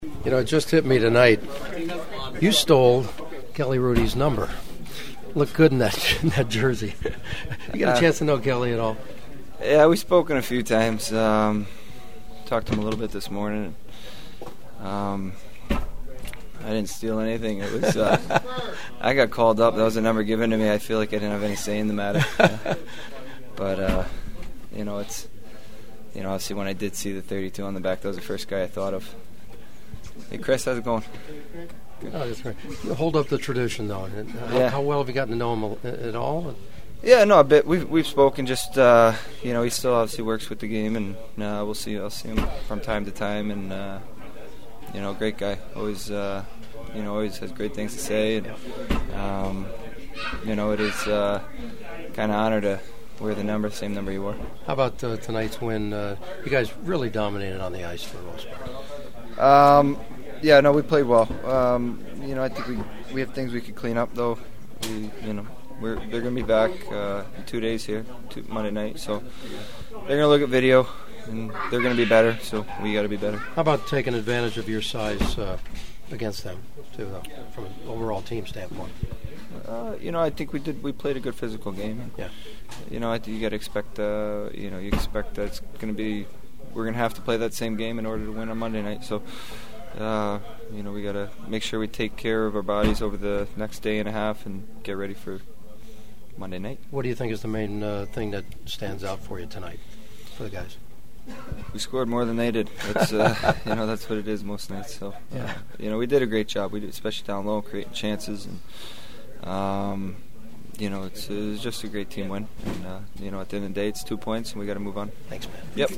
Kings goalie Jonathan Quick: